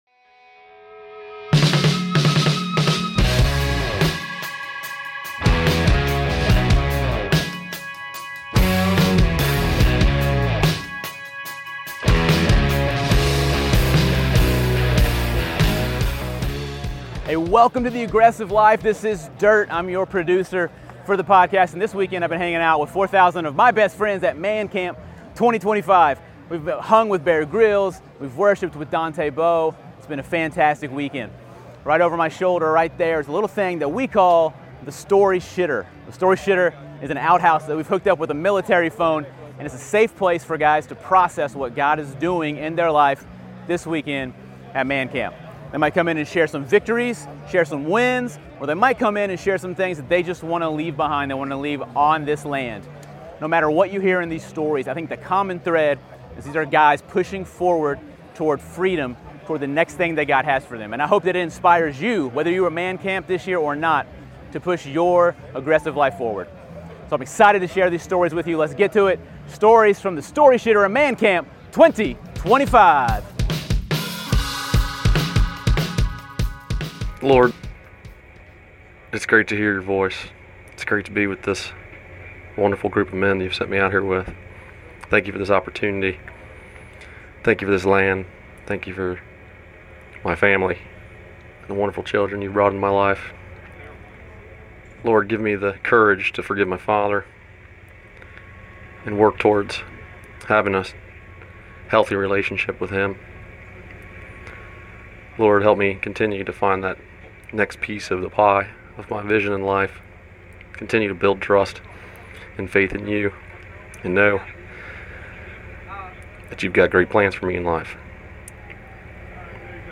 This episode is a small snapshot into all the God did at MAN CAMP 2025.